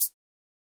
Perc (13).wav